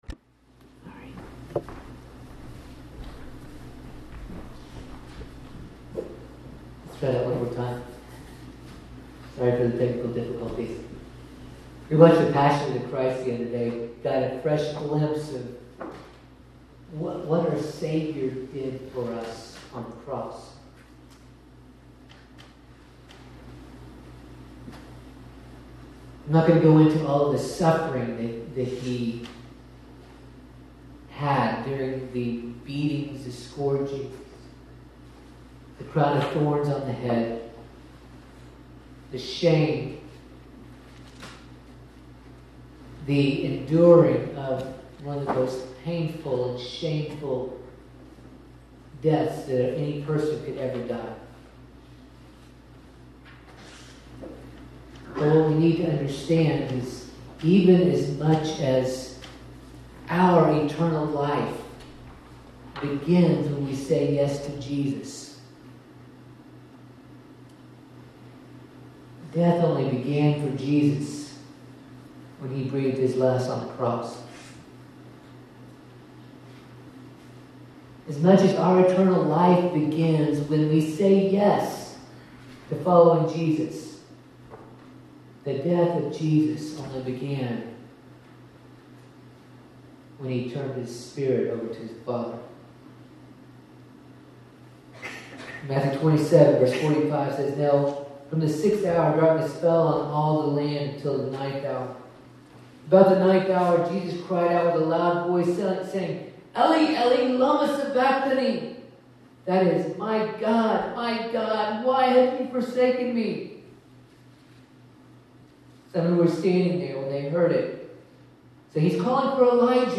This message was preached at FCC in Franklin, CT.